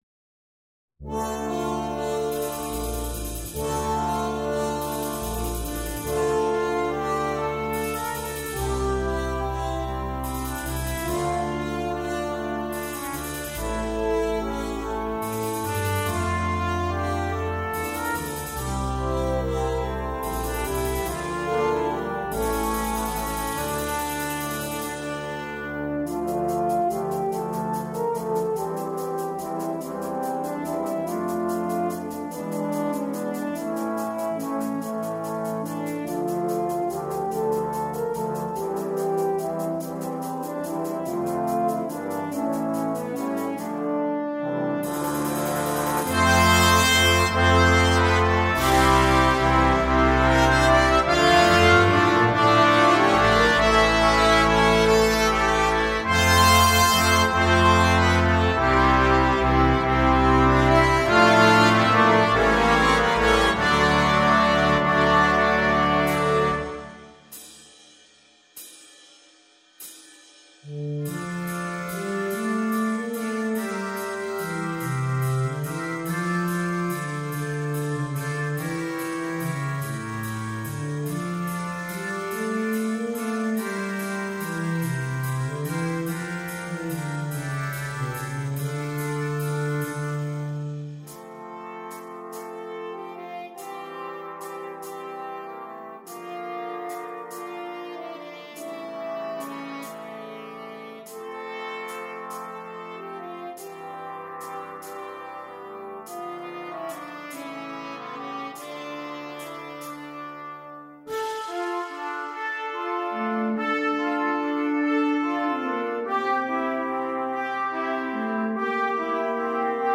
2. Blechbläserensemble
10 Blechbläser
Klassik